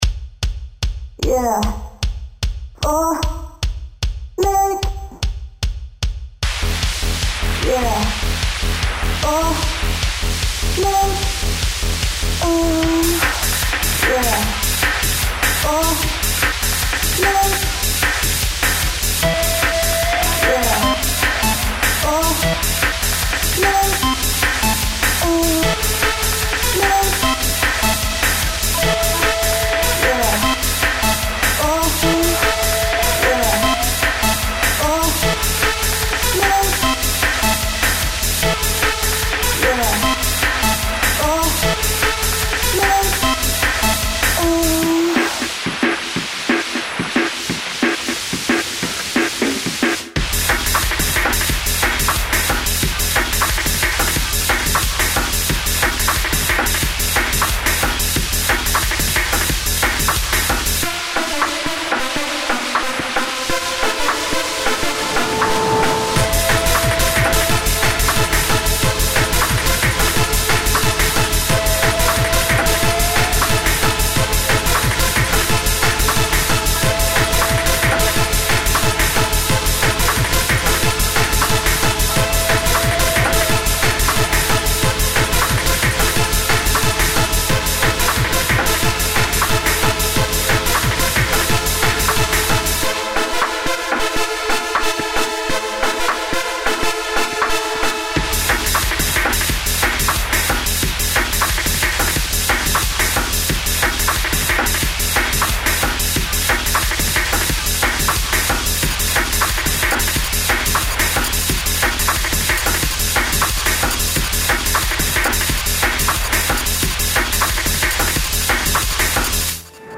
テクノ系など電子音を駆使した楽曲が中心になりますので、そういった素材をお求めの方はこちらのページをご確認下さい。